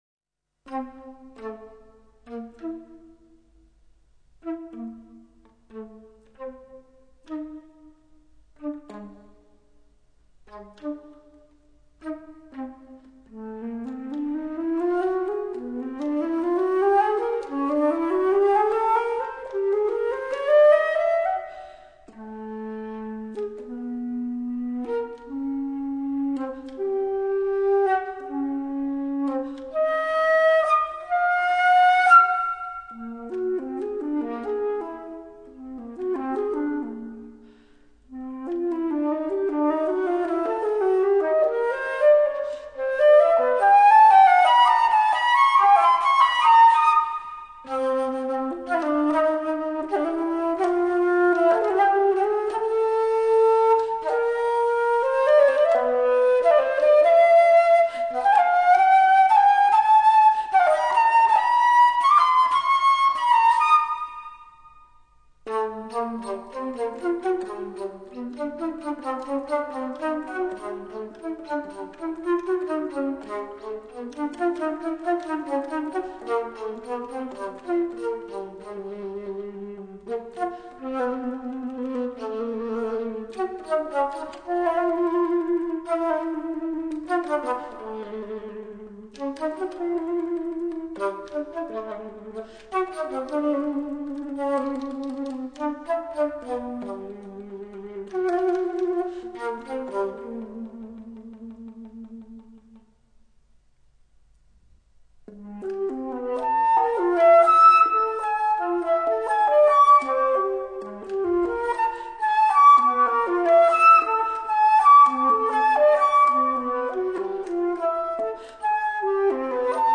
Alto flute